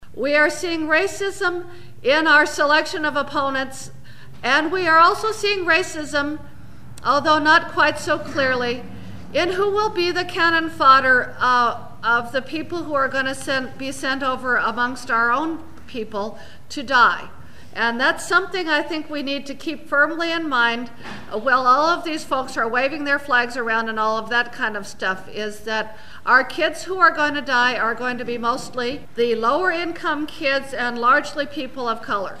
speech at the antiwar organizing meeting in Oakland 9/21/01